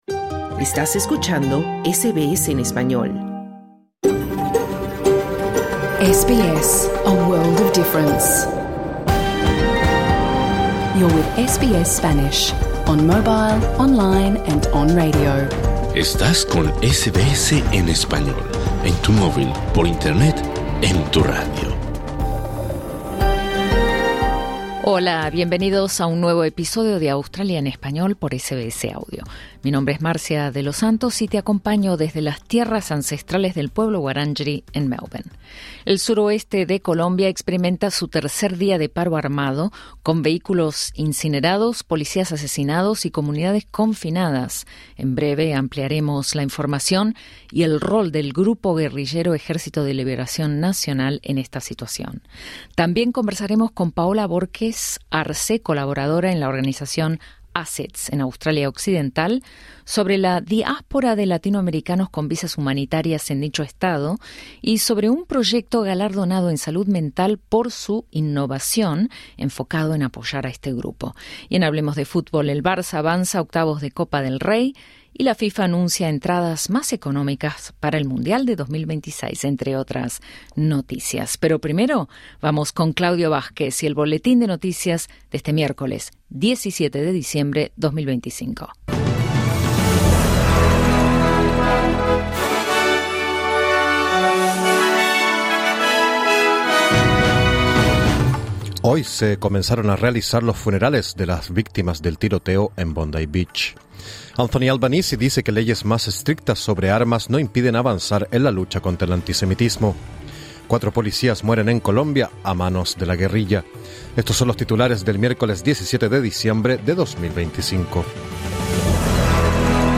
Programa en Vivo | SBS Spanish | 14 de marzo 2024 Credit: Getty Images